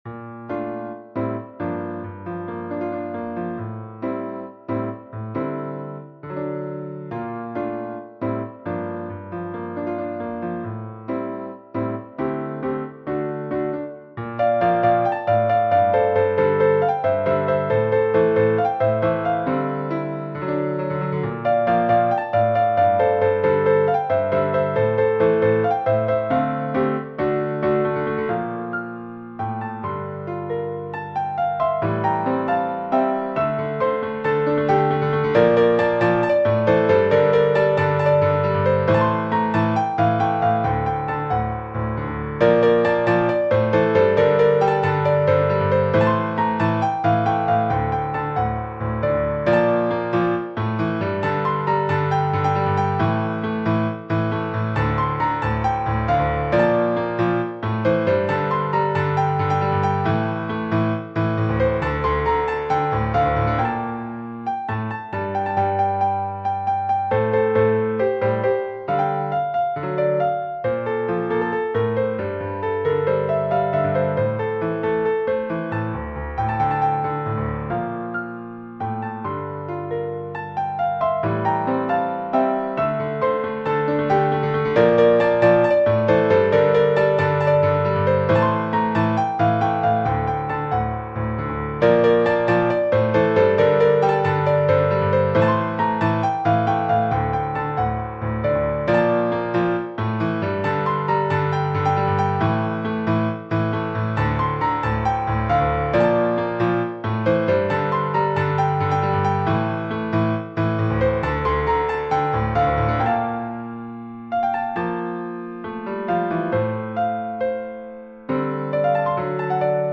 F major, D minor PDF3